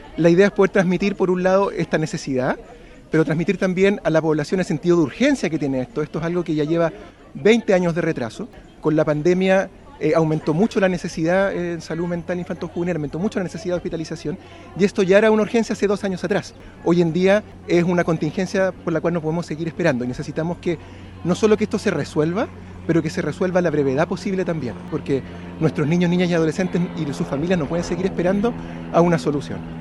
Testimonios de la protesta